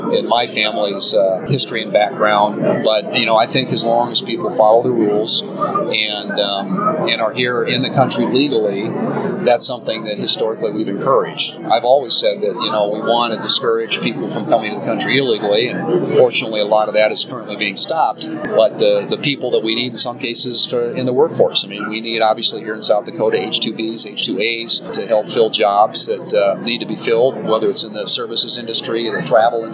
WATERTOWN, S.D.(KXLG)- U.S. Senator John Thune visited Watertown Thursday to address the local Rotary Club during their meeting held at the Elks Lodge.